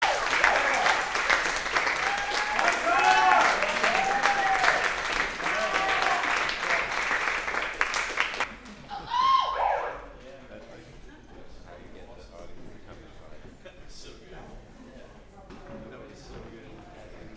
Live from BuddyFest: Buddyfest (Audio)